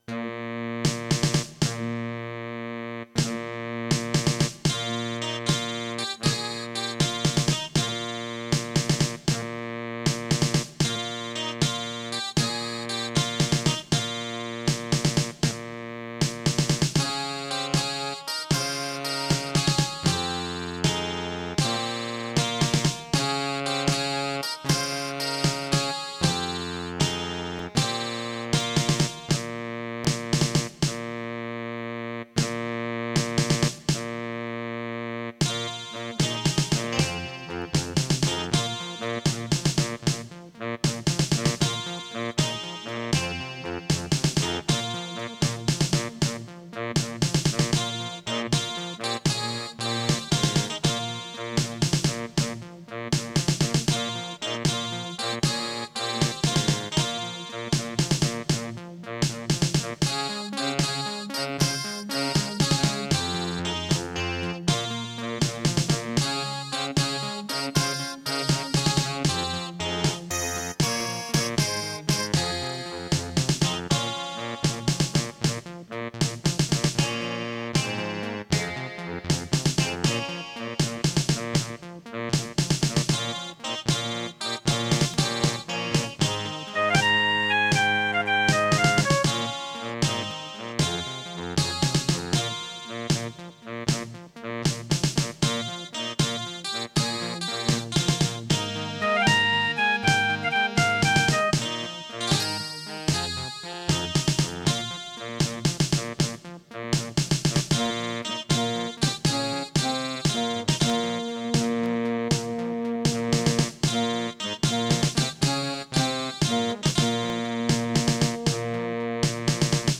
* Some records contain clicks.